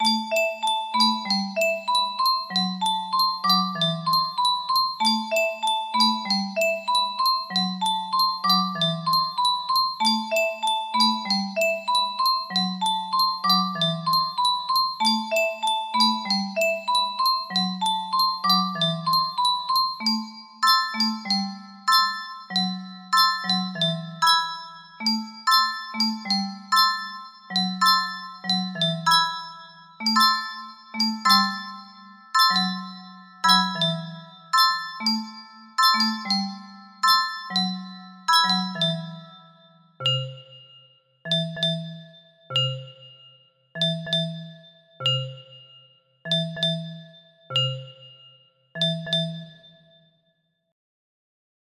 02-iskala music box melody
Full range 60